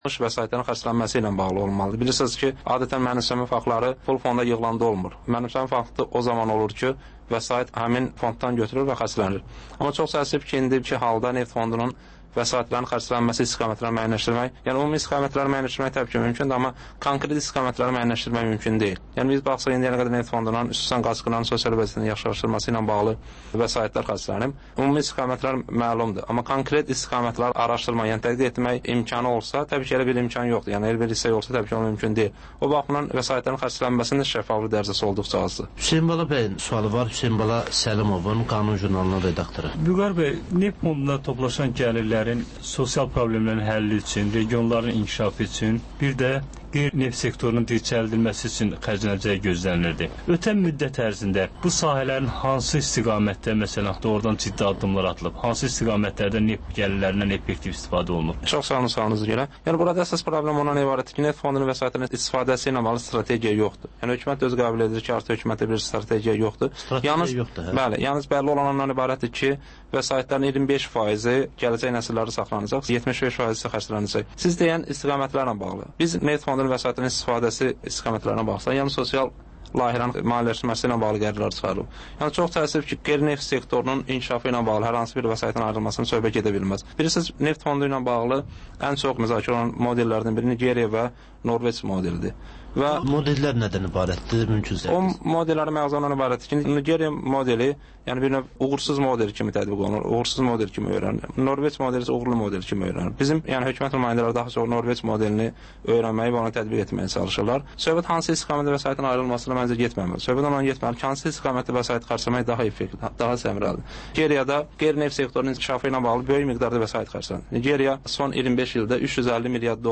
Azərbaycan, Gürcüstan ve Ermənistandan reportajlar, müzakirələr